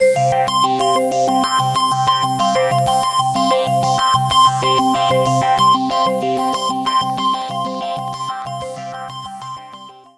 • Качество: 320, Stereo
громкие
без слов
Electronica
звонкие